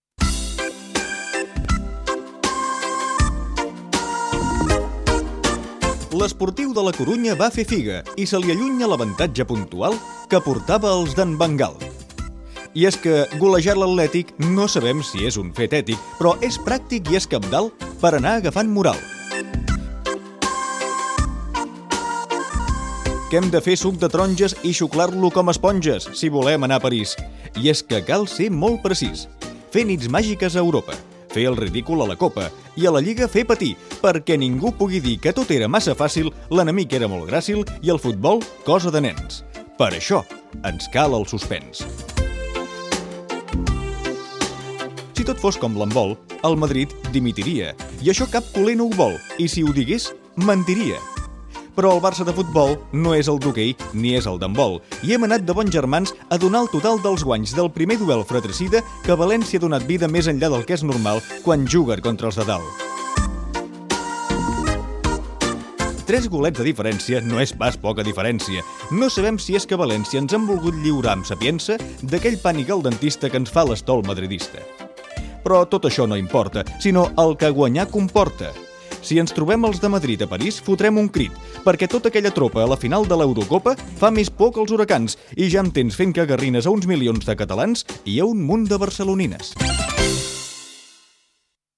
Esportiu
Programa presentat per Joan Maria Pou.